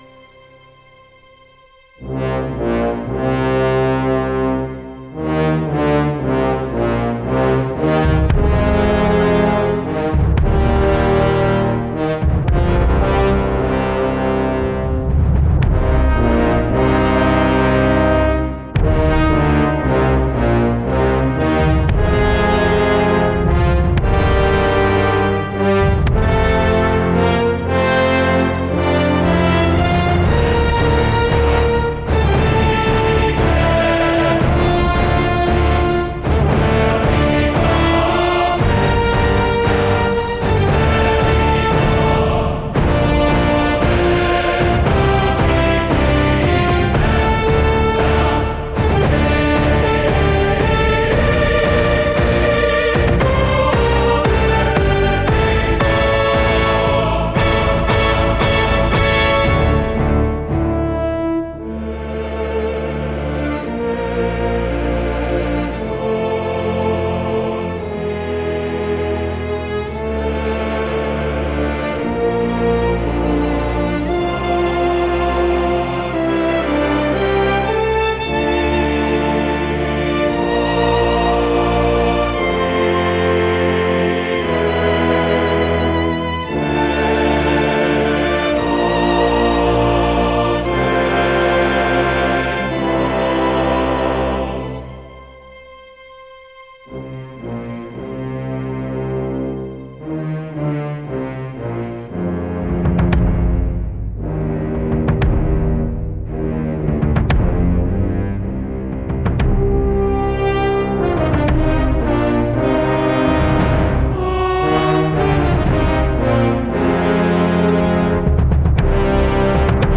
Cinematic Classical Film Score